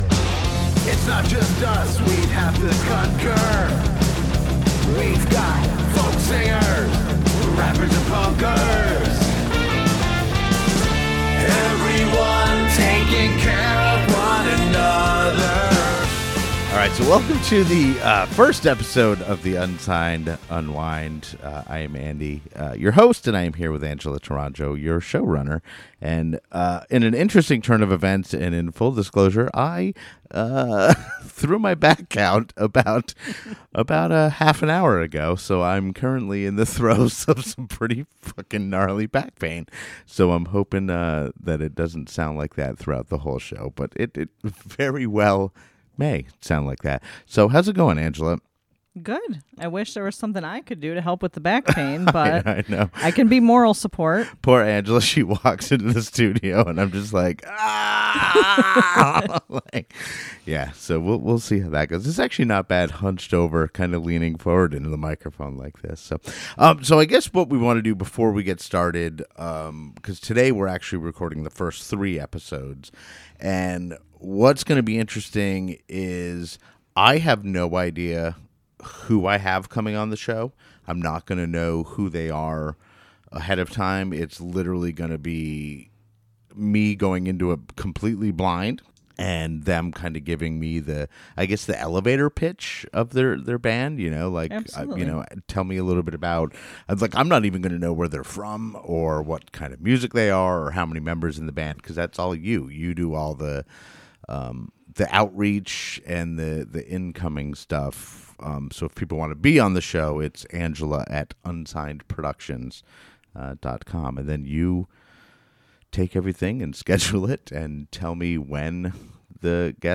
This show is an attempt to gather great local music from all over the US and Canada, have a brief conversation with the band/musician and play one of their songs. My goal is that local-music enthusiasts, such as myself, can discover great local music that otherwise may have remained hidden to them.